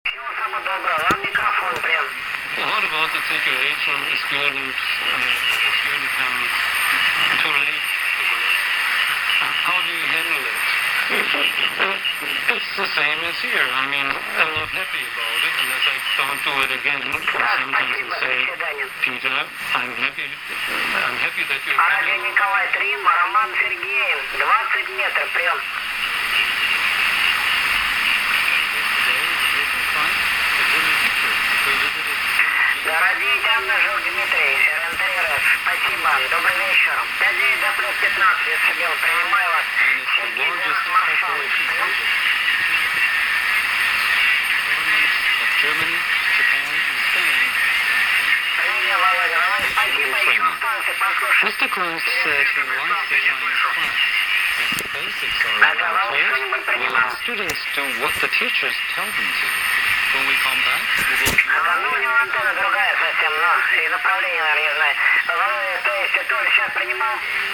Т.к. я впервые слушаю ППП с конвертером, предлагаю вам послушать помехи радиовещательной станции в SSB участке 20- метрового диапазона.